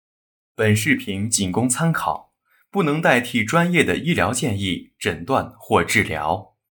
All voiceover projects are recorded and mixed by our in-house audio engineers to ensure high fidelity and natural sounding recordings.
Some of our Voice Over Samples
Chinese – Male voice
2023-Chinese-Male.wav